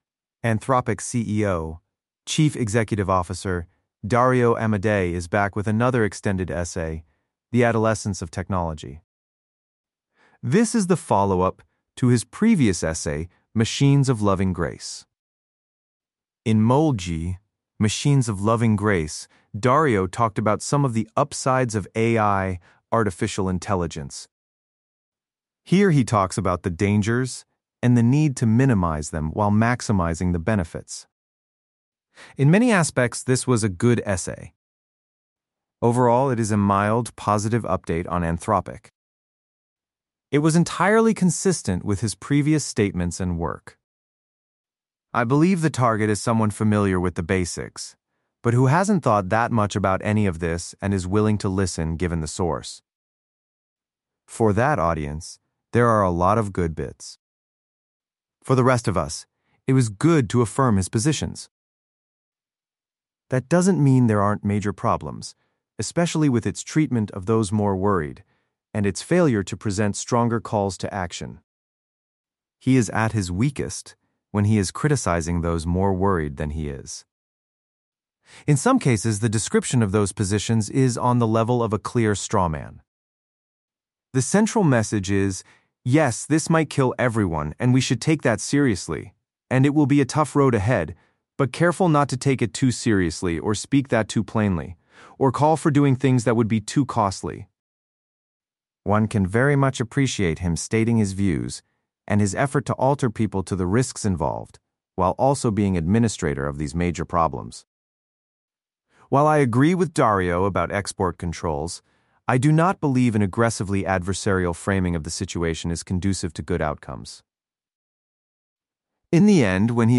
Your instructor made this AI-generated audio version of the Substack post for fun.